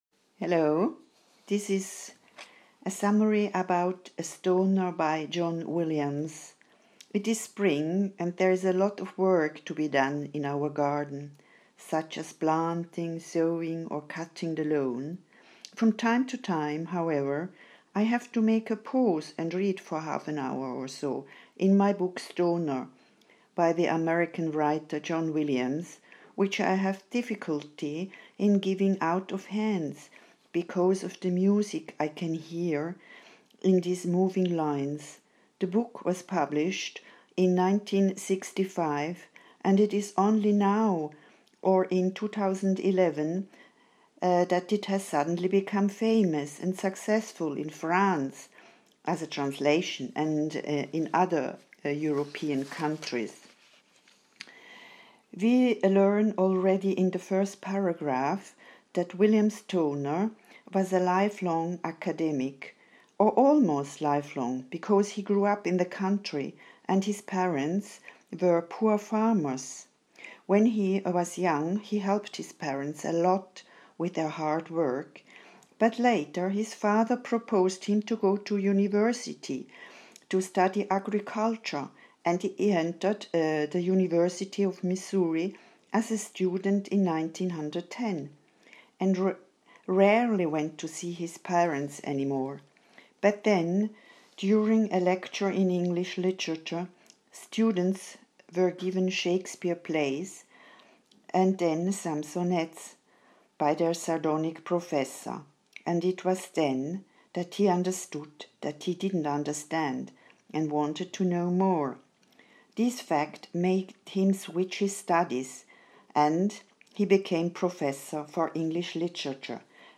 Audio of my summary in English: